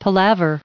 1794_palaver.ogg